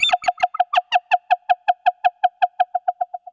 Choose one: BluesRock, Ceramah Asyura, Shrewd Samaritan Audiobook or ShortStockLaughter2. ShortStockLaughter2